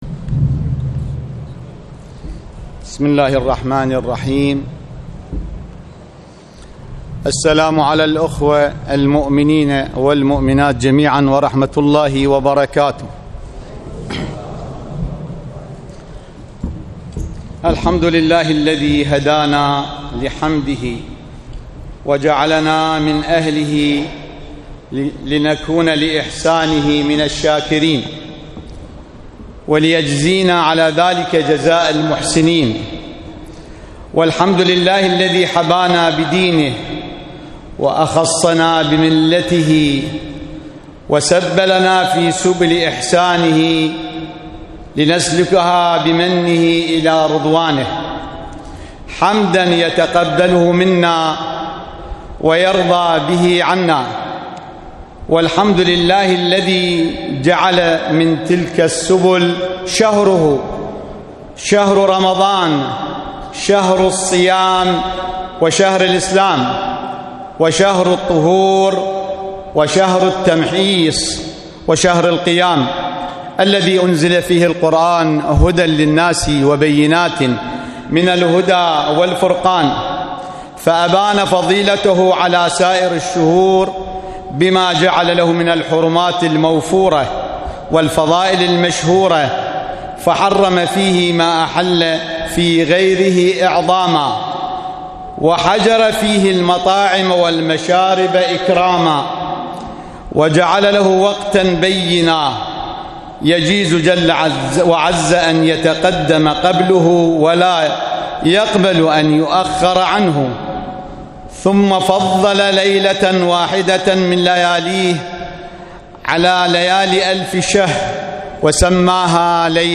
صلاة الجمعة في مدينة الناصرية - تقرير صوتي مصور -
للاستماع الى خطبة الجمعة الرجاء اضغط هنا